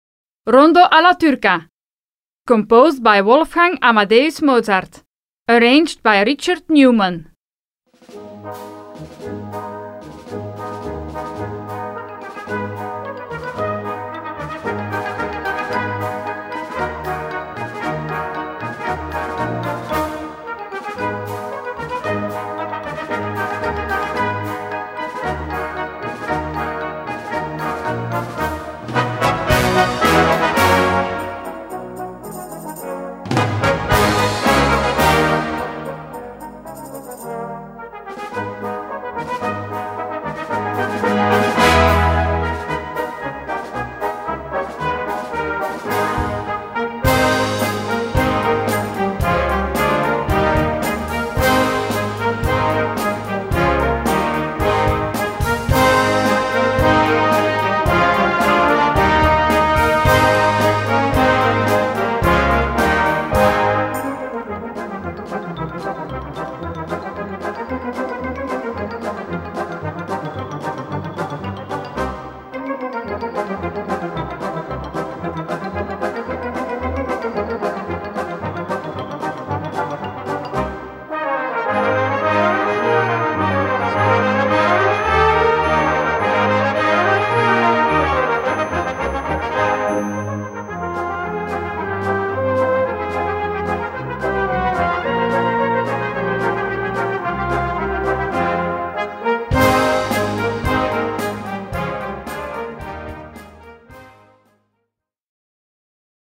Gattung: Konzertante Blasmusik
Besetzung: Blasorchester
Dieses Werk ist in einem traditionellen Stil gehalten.